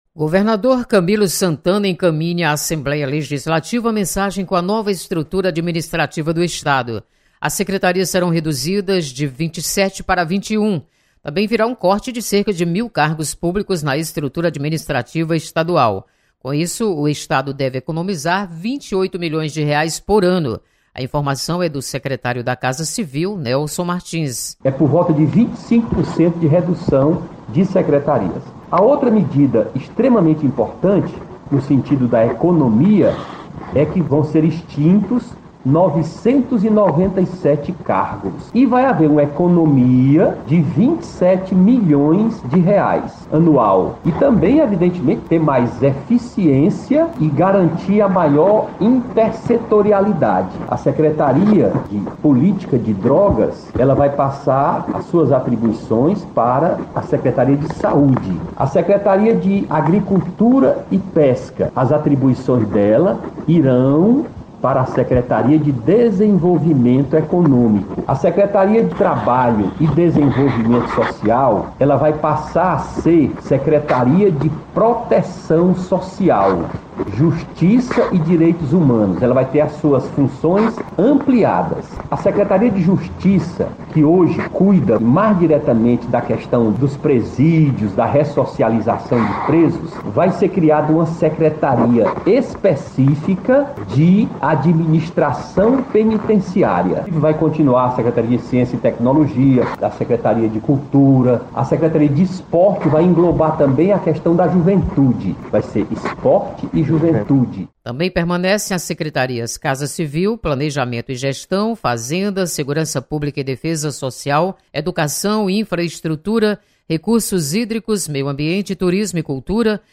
Assembleia recebe Mensagens do Executivo sobre reforma administrativa. Repórter